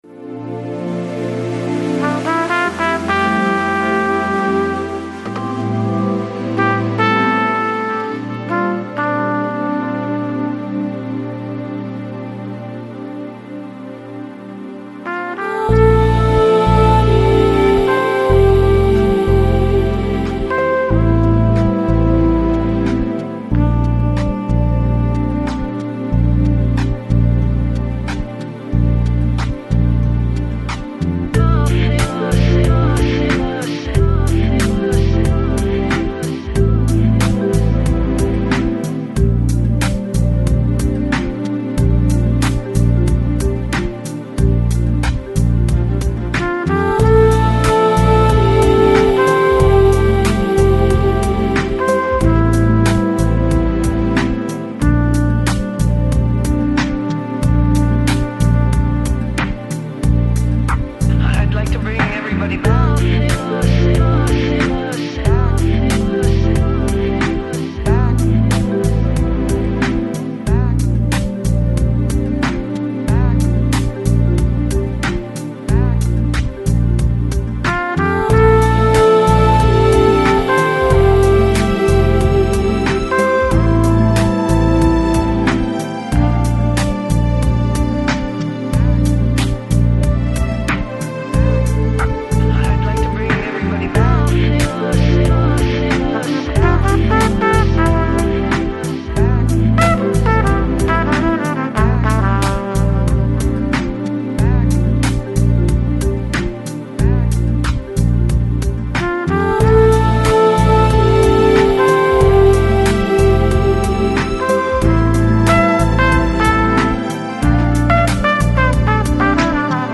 Chill Out, Lounge, Downtempo